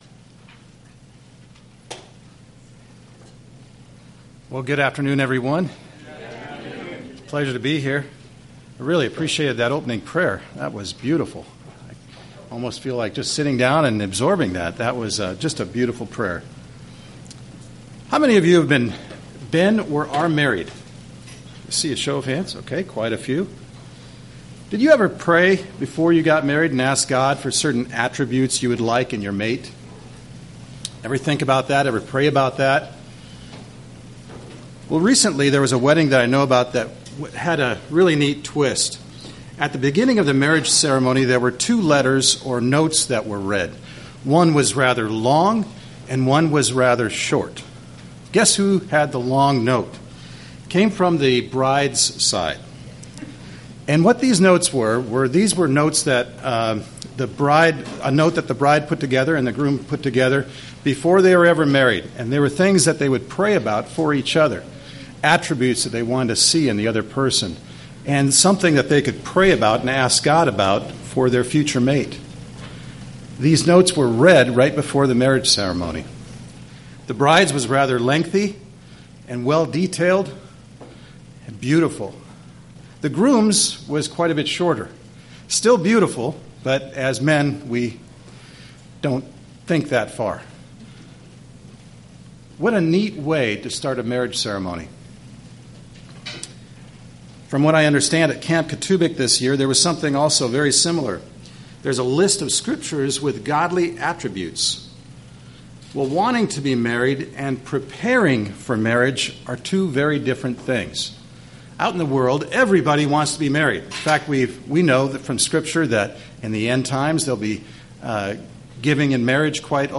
Given in Redlands, CA
UCG Sermon